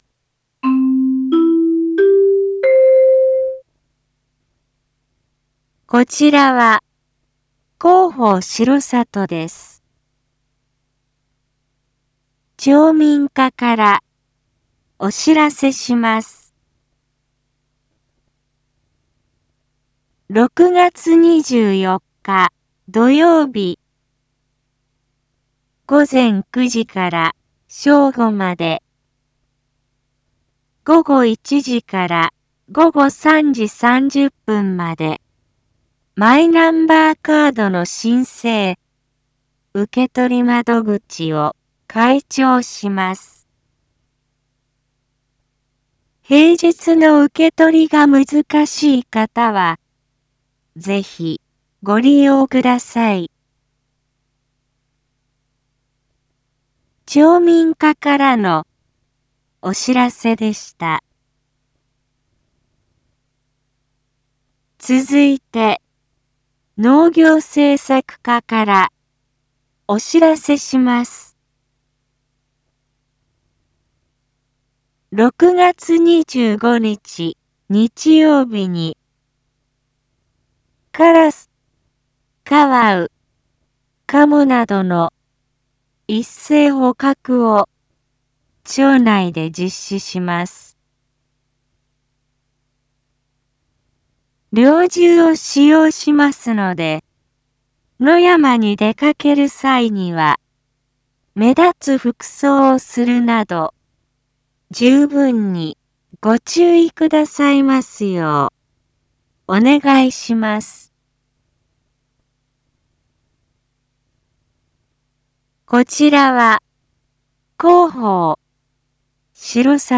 一般放送情報
Back Home 一般放送情報 音声放送 再生 一般放送情報 登録日時：2023-06-23 19:02:09 タイトル：6月 マイナンバーカード窓口 インフォメーション：こちらは、広報しろさとです。